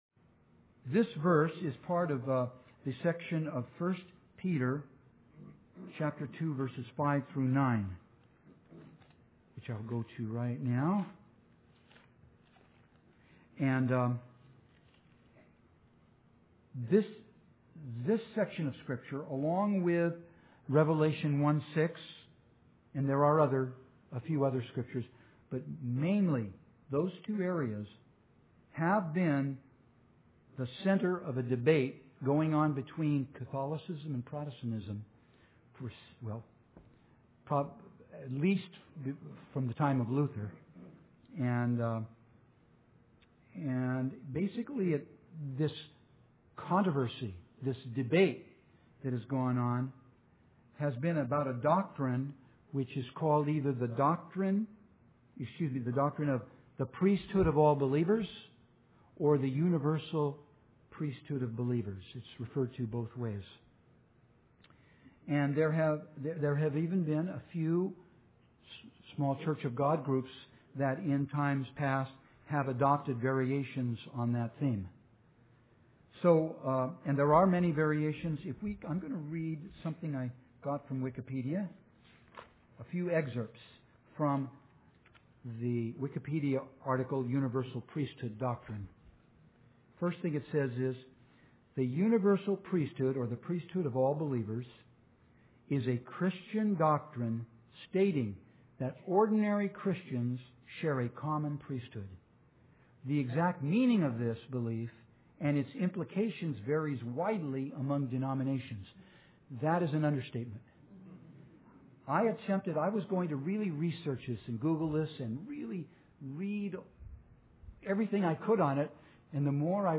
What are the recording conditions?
Given in San Francisco Bay Area, CA